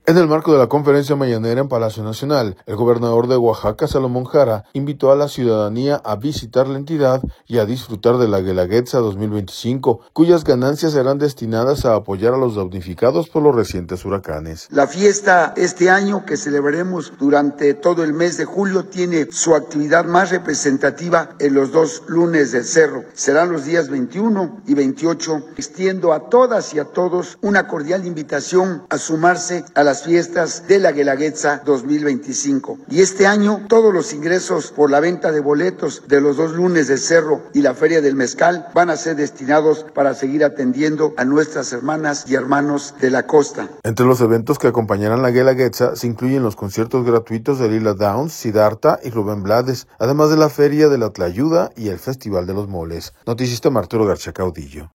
En el marco de la conferencia Mañanera en Palacio Nacional, el gobernador de Oaxaca, Salomón Jara, invitó a la ciudadanía a visitar la entidad y disfrutar de la Guelaguetza 2025, cuyas ganancias serán destinadas a apoyar a los damnificados por los recientes huracanes.